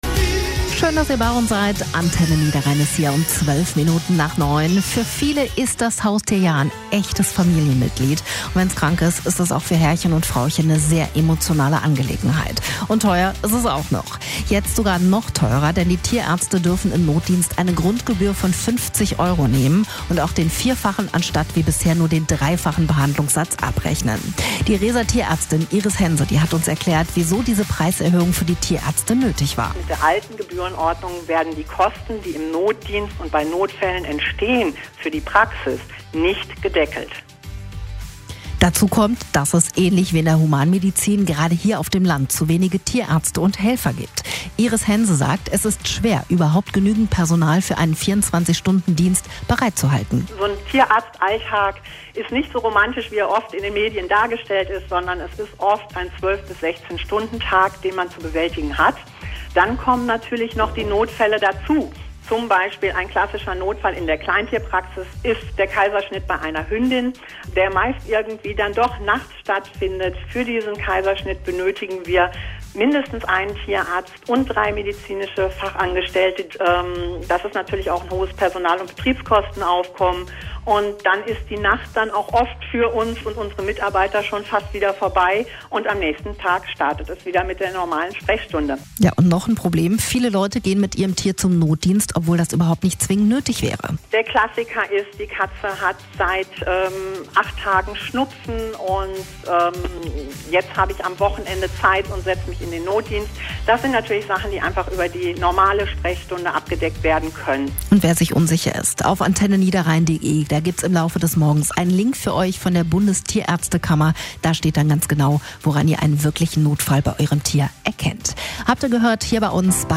Tierarztkosten Interview Tierärztin aus Rees